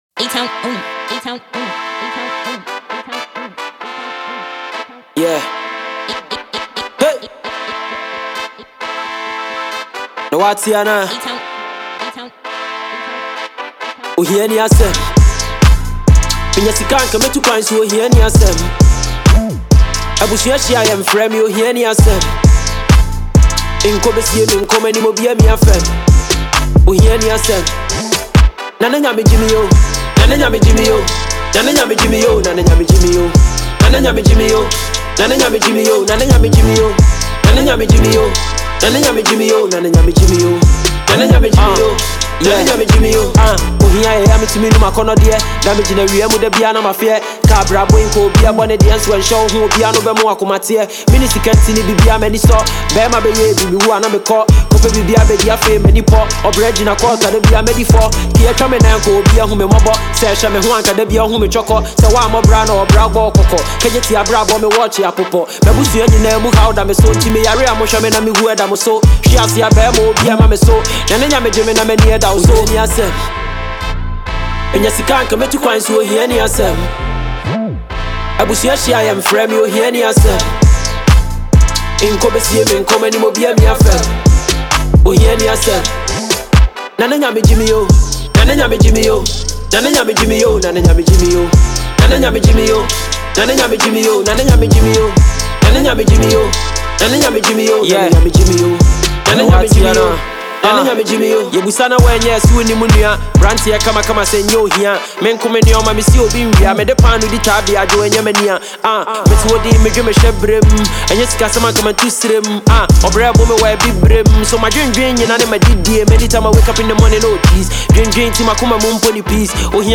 a Ghanaian rapper
a solo track.